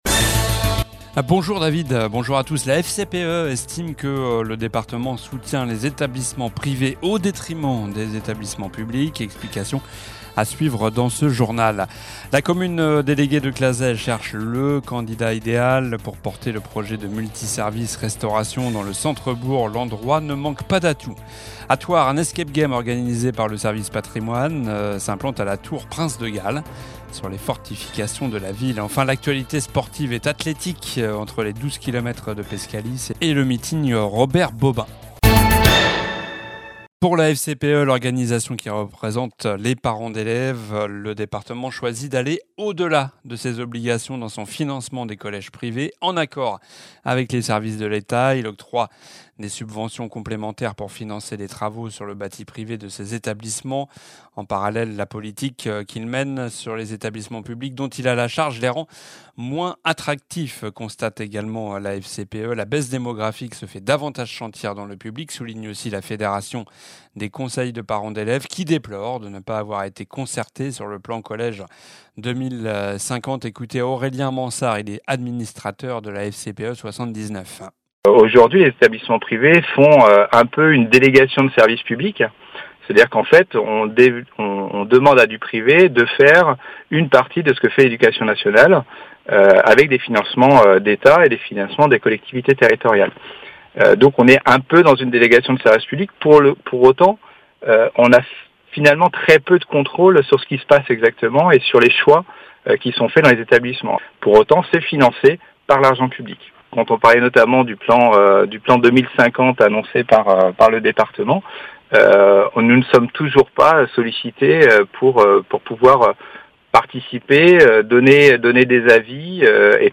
Journal du mardi 15 juillet (midi)